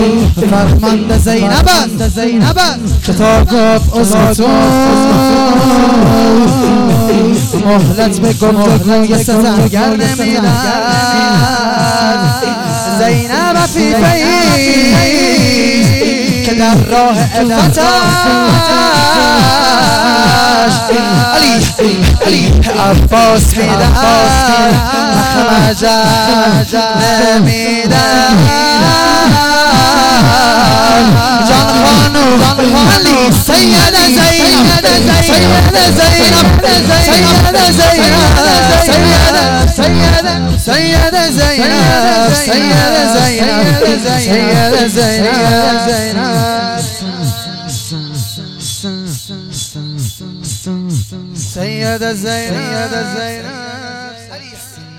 ذکر و رجز | فرمانده زینب است